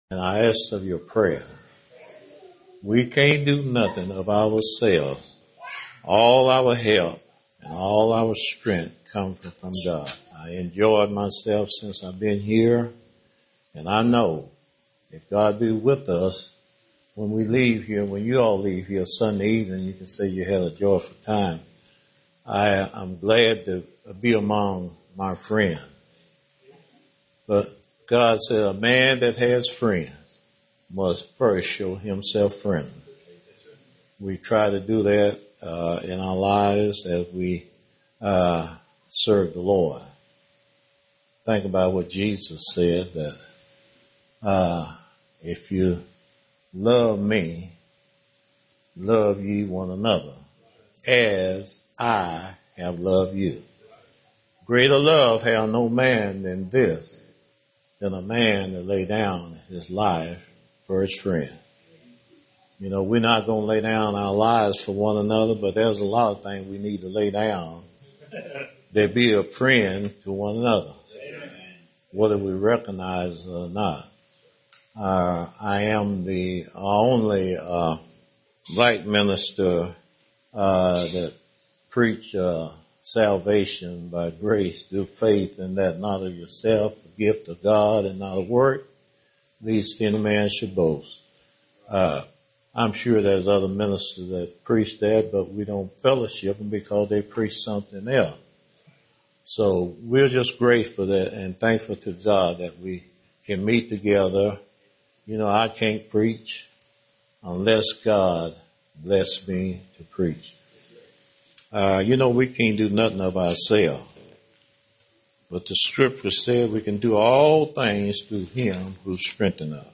Sermons given during Annual meeting in July • Page 11